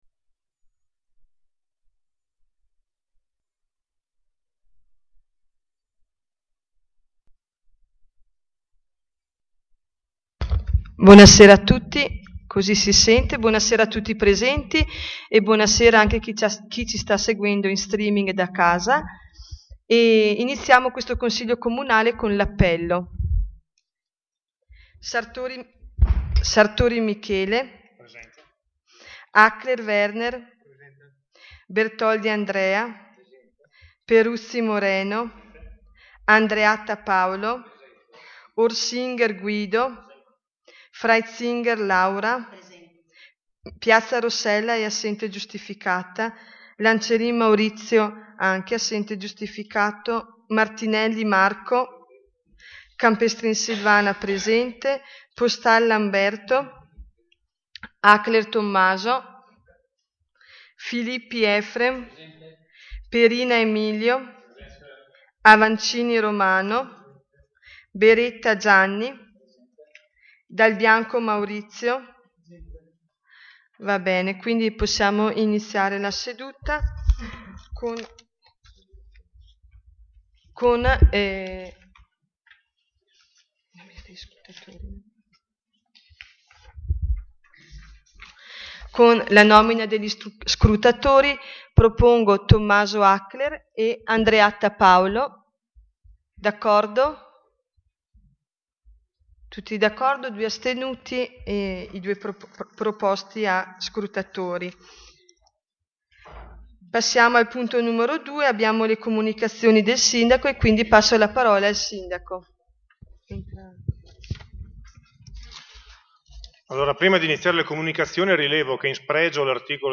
Audio della seduta